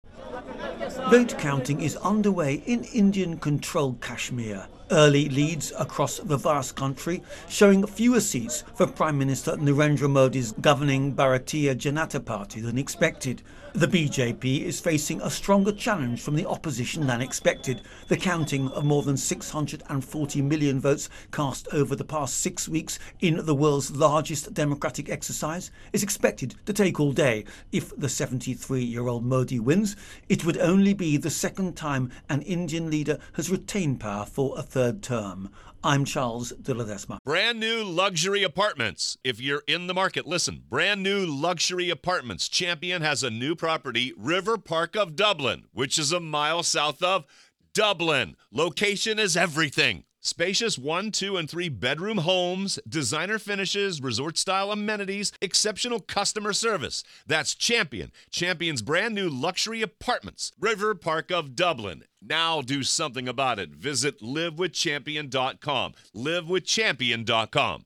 ((Begins with sound))